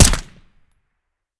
m4a1-2.wav